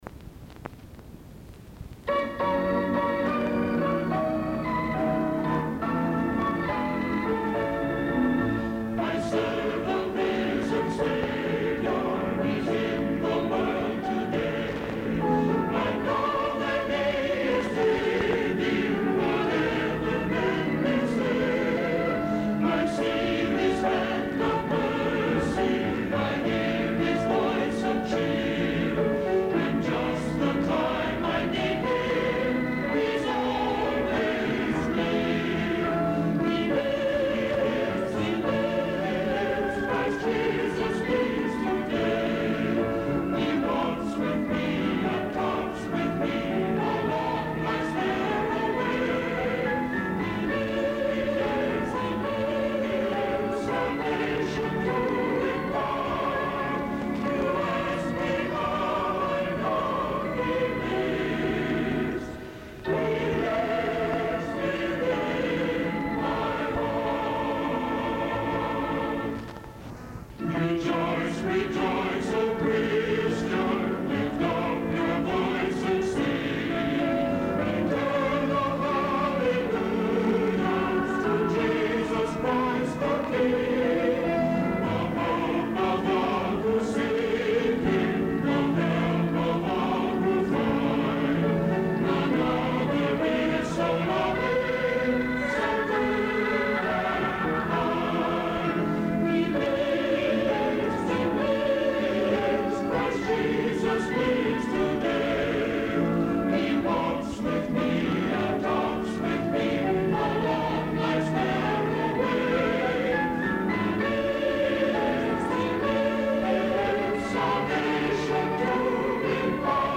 A qualidade da gravação não está lá muito boa – confesso… É mais um fruto de conversão de minha velha fita cassete, de quase trinta anos de idade.